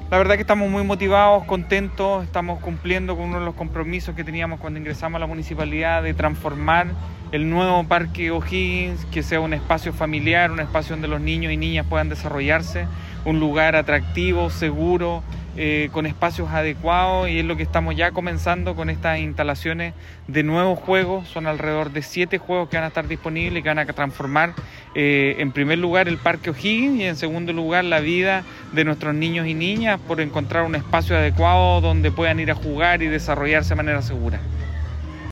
AUDIO : Alcalde Ali Manouchehri
Ali-Manouchehri-sobre-instalacion-de-nuevos-juegos-en-Parque-O´Higgins.mp3